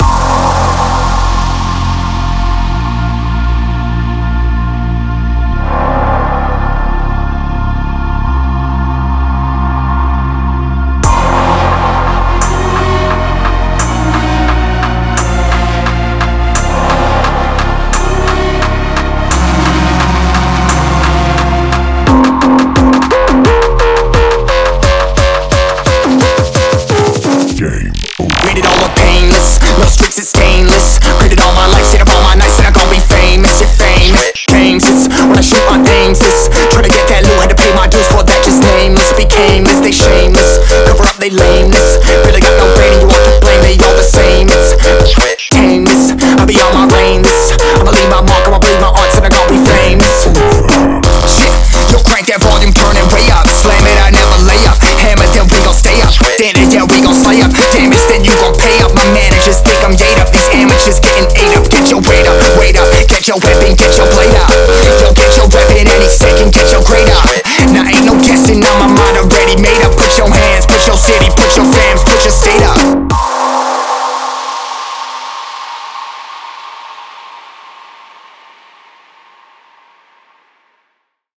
DRUM & BASS (Jump Up)